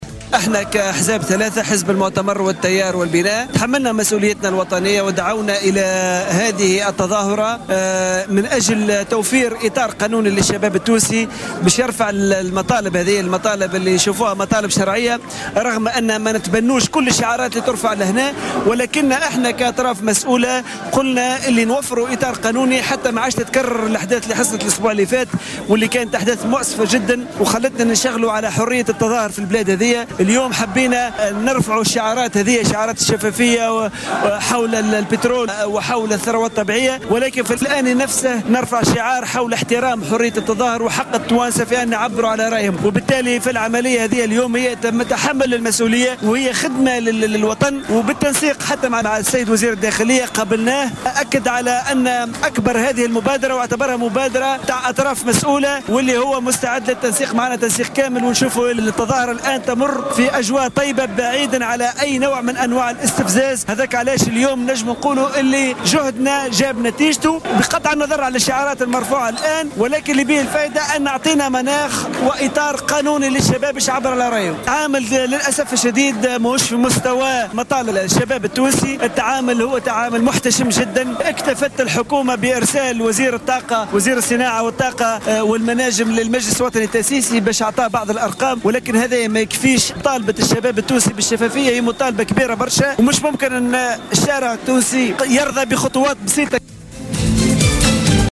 عماد الدايمي من مسيرة وينو البترول : تعامل الحكومة مع الحملة محتشم وغير مرضي
تجمع عدد من المواطنين بمحيط المسرح البلدي بشارع الحبيب بورقيبة بالعاصمة في إطار حملة وينو البترول، ودعا اليه تجمع البناء الوطني، التيار الديمقراطي والمؤتمر من أجل الجمهورية.